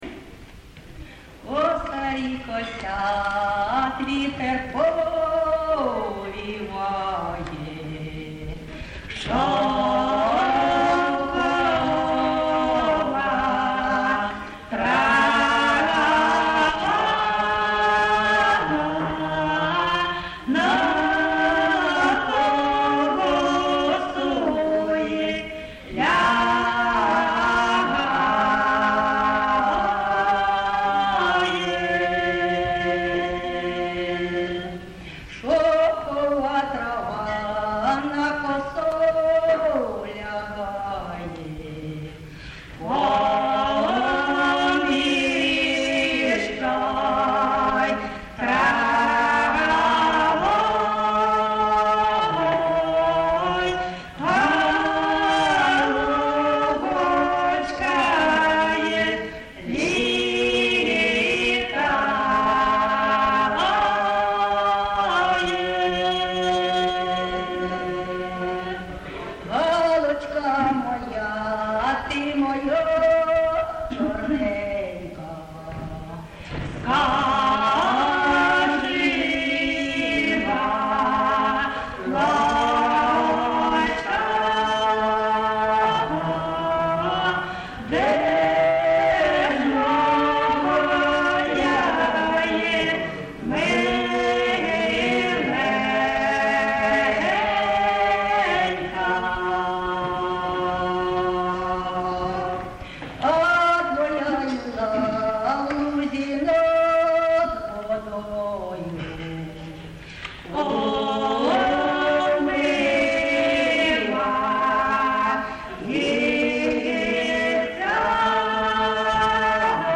ЖанрПісні з особистого та родинного життя
Місце записус-ще Зоря, Краматорський район, Донецька обл., Україна, Слобожанщина